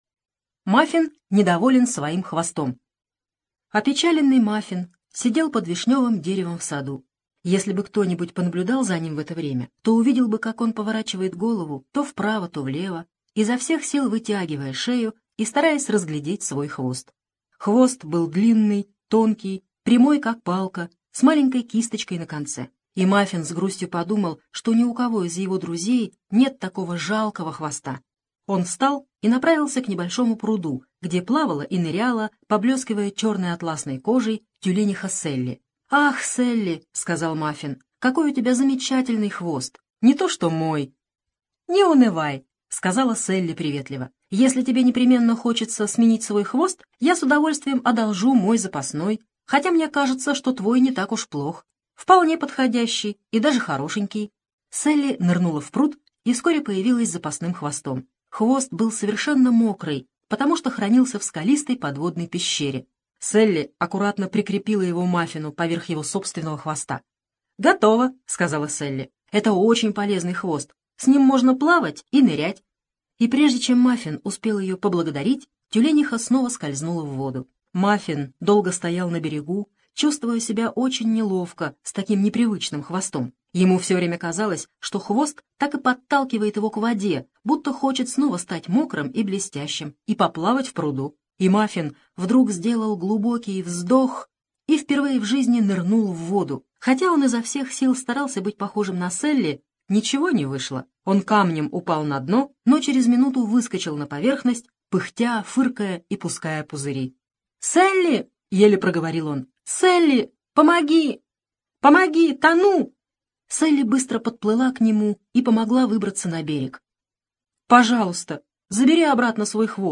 Мафин недоволен своим хвостом — аудиосказка Хогарт